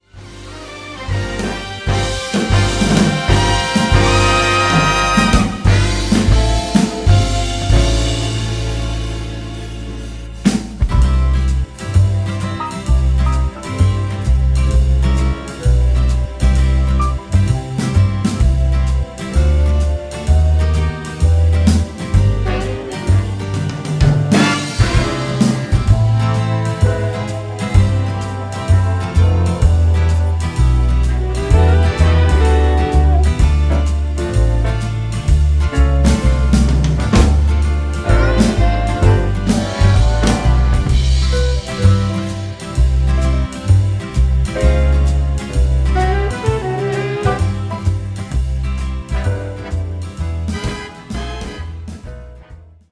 big band